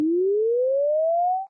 arc_grow.ogg